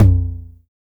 909 TOM LO.wav